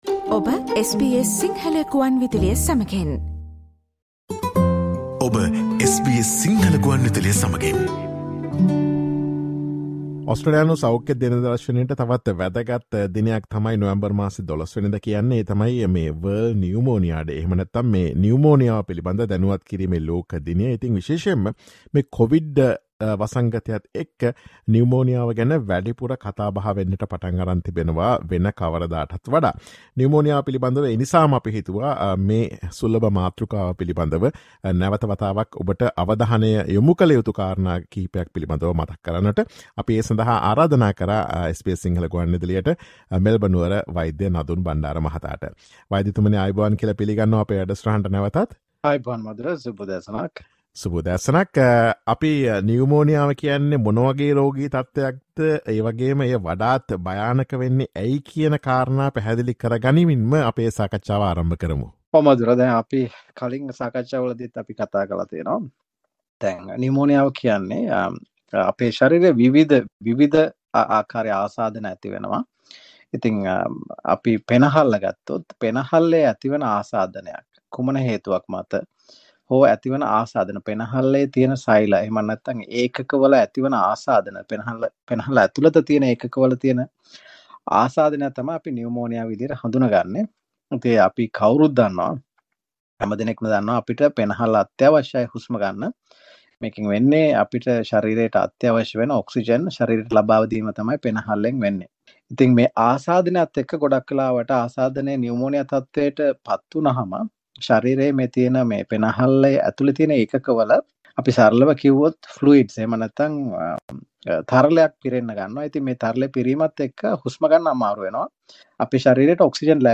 Listen to SBS Sinhala Radio's discussion on Pneumonia and Covid Pneumonia.